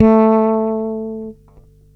35-A3.wav